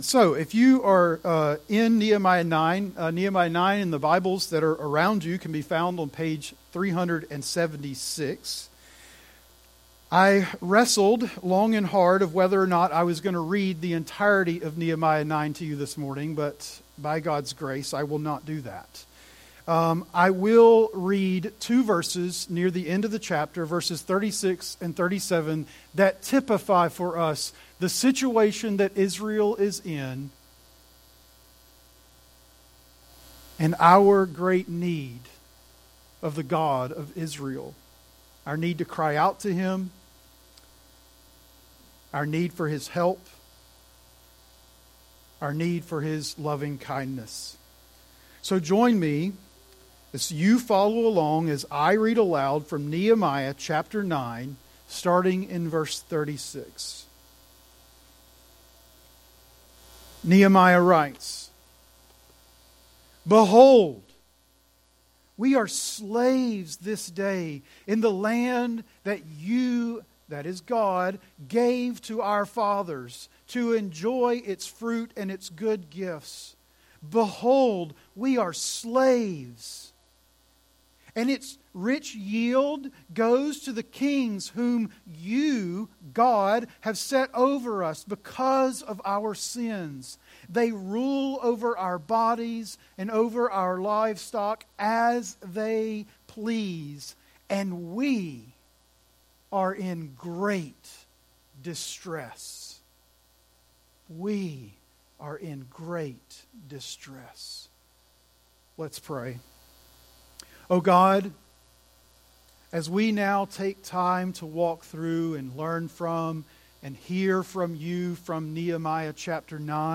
Series: One-Off Sermons